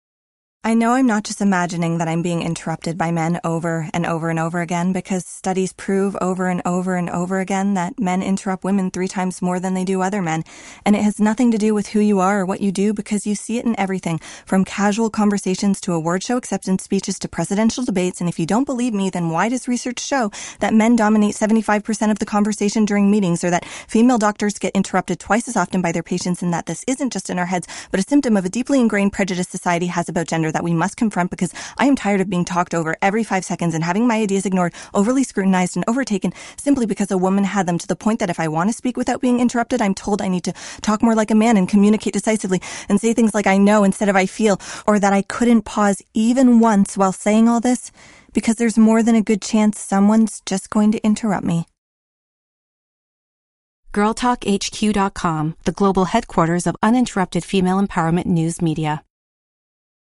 SilverCraft - Copywriting